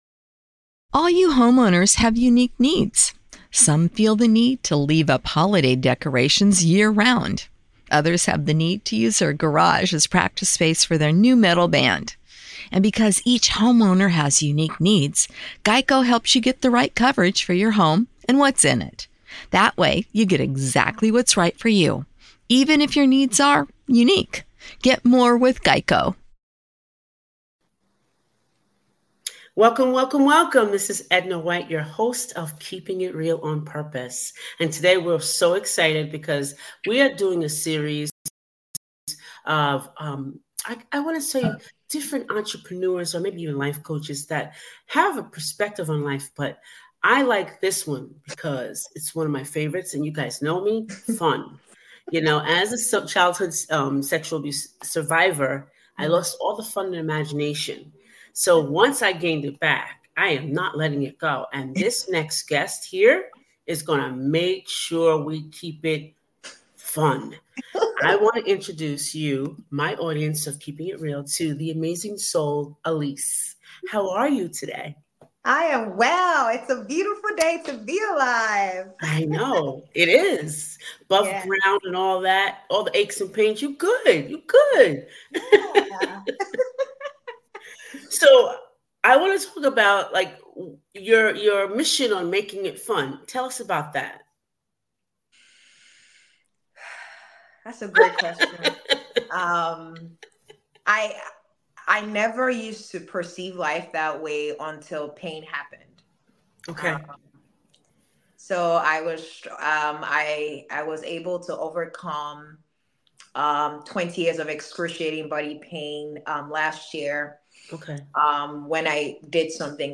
a renowned life coach and public speaker.